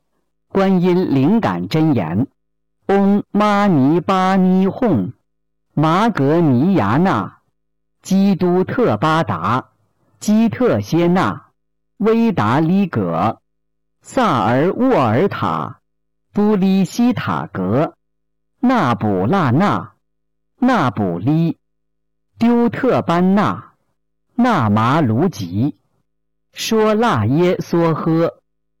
014《观音灵感真言》教念男声